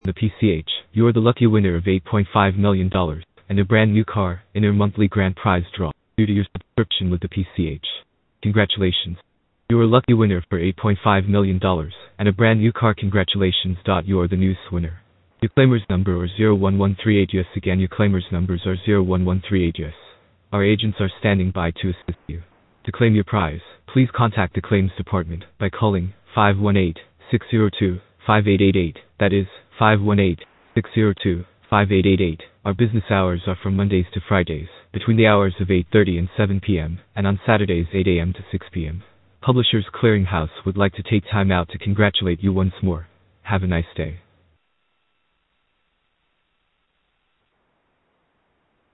Scams Robo Calls
It’s also worth listening to the robocall recording, certainly a different robo-voice type and style.